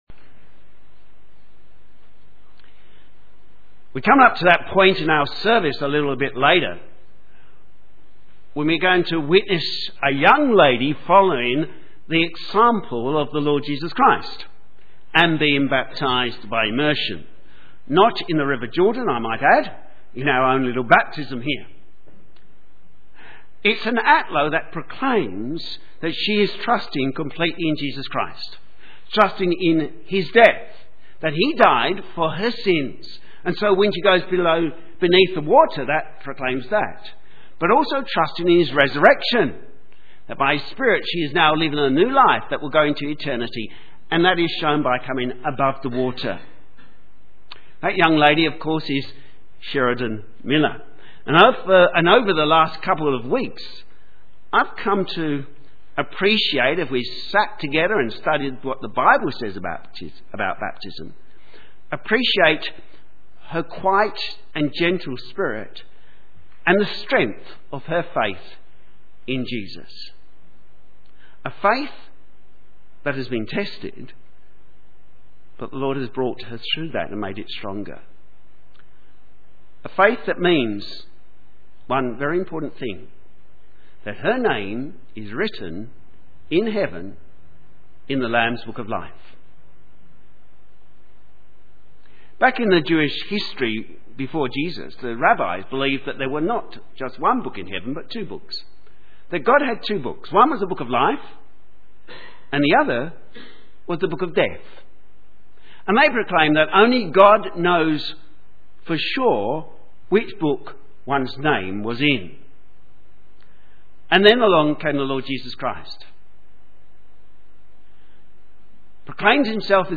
Sermon
BelieversBaptismSermon-334.mp3